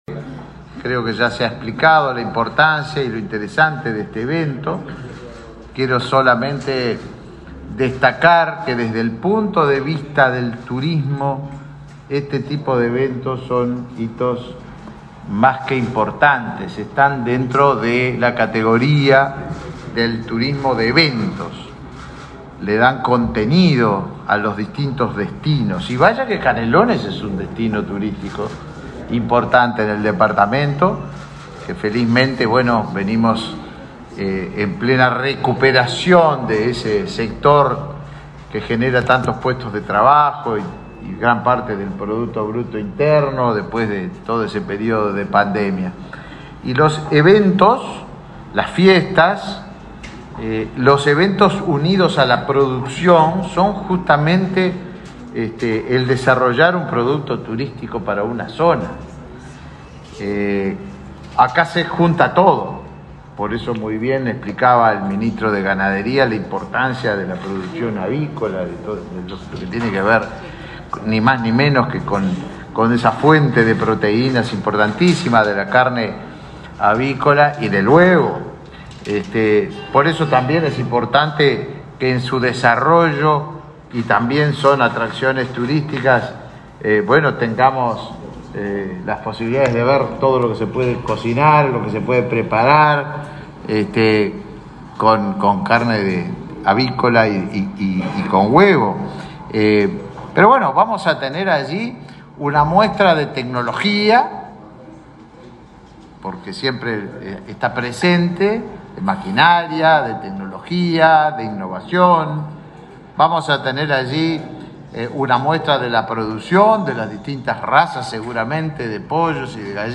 Palabras de autoridades en lanzamiento de 11.ª edición de la Expo Avícola
El ministro de Turismo, Tabaré Viera, y su par de Ganadería, Fernando Mattos, participaron en el lanzamiento de la 11.ª edición de la Expo Avícola,